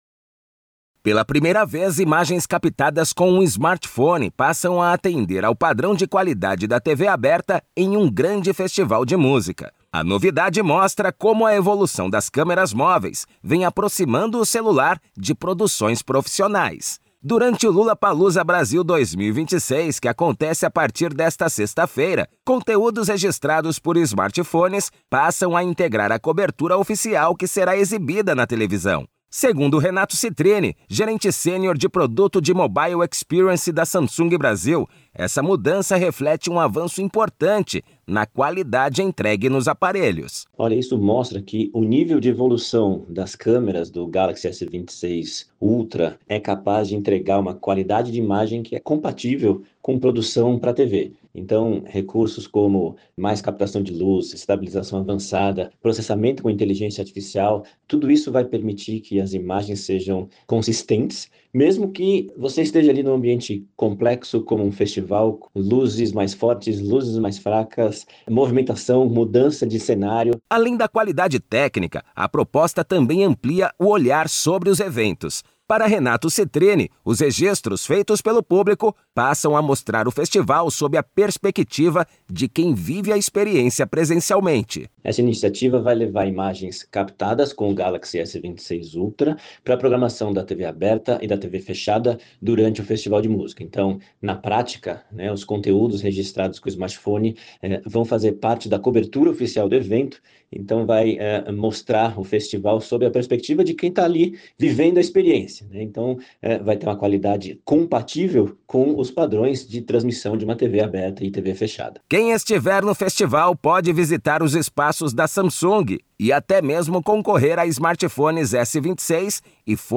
Materiais de Imprensa > Radio Release